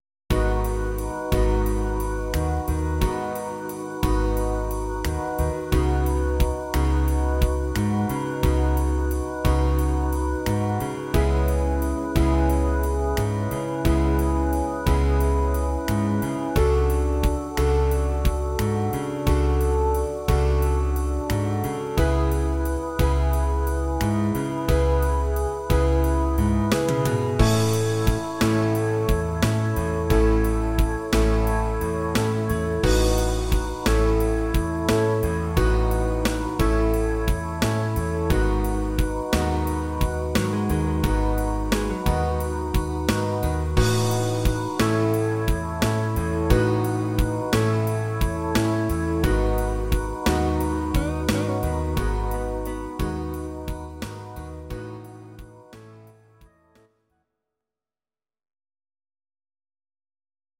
Audio Recordings based on Midi-files
Jazz/Big Band, Instrumental, 1960s